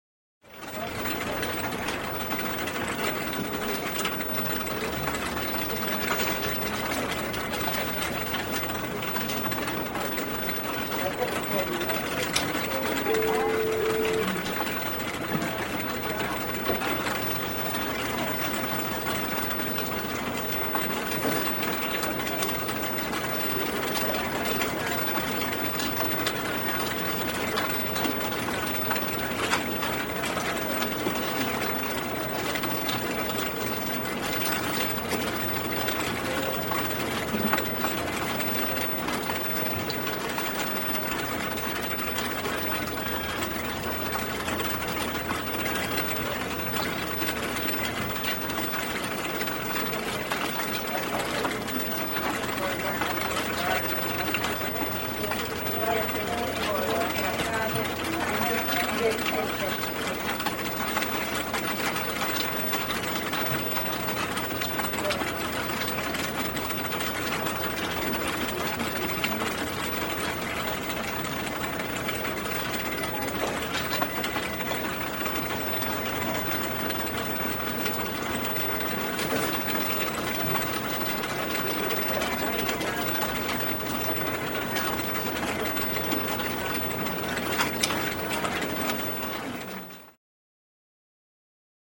Полицейский участок: голоса, телефоны, окружение Скачать звук music_note Офис , фирма save_as 3.9 Мб schedule 4:22:00 4 6 Теги: wav , атмосфера , Голоса , звук , обстановка , офис , помещения , телефон